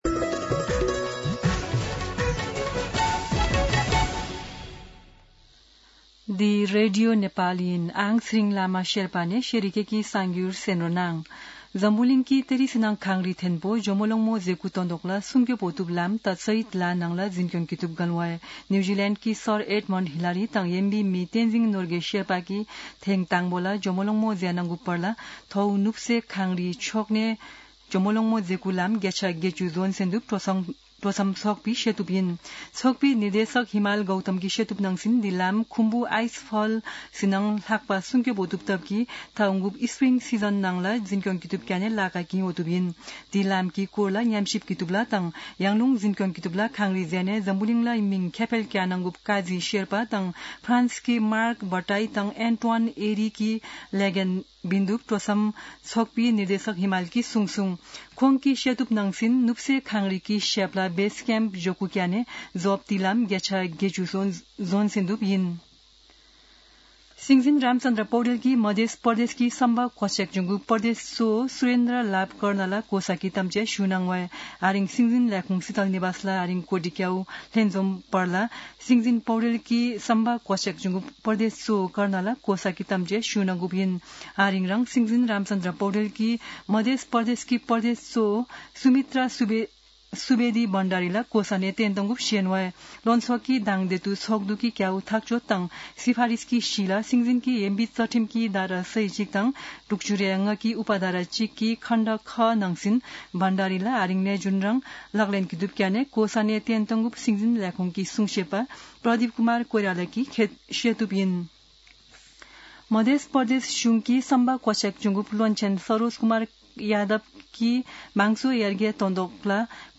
शेर्पा भाषाको समाचार : २५ कार्तिक , २०८२
Sherpa-News-25.mp3